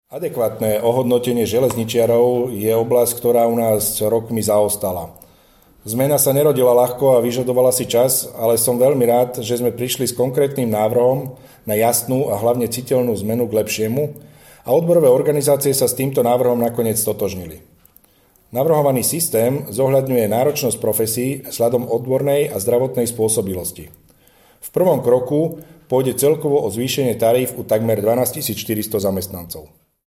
Nahrávka tlačovej správy